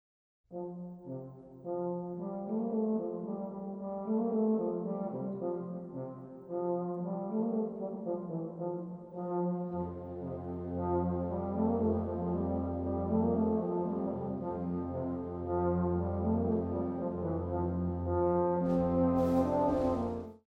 Euphonium/Baritone
Bands, however depend on the euphonium to fill out the low brass section with its smooth, beautiful sound.
baritone1.mp3